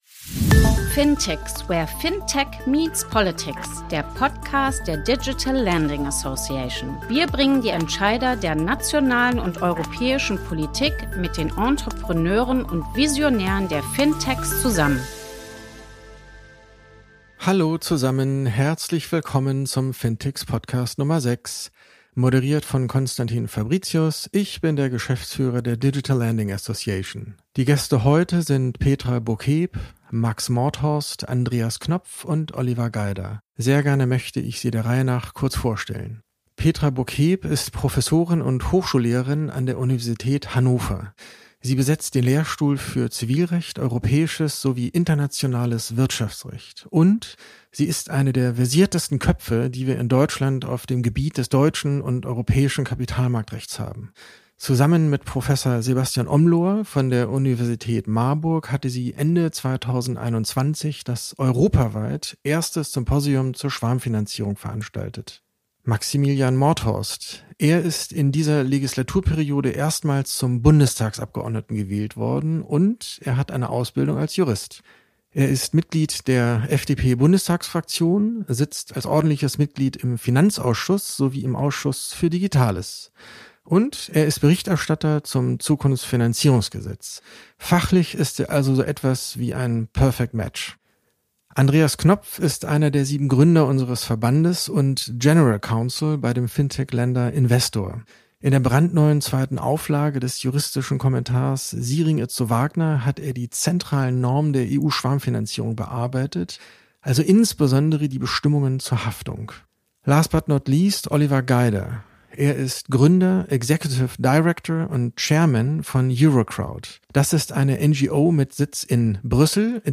Eine hochqualifizierte Runde spürt der Antwort nach, diskutiert die aktuelle Rechtslage, die Vorschläge der Bundesregierung und alternative Lösungsansätze.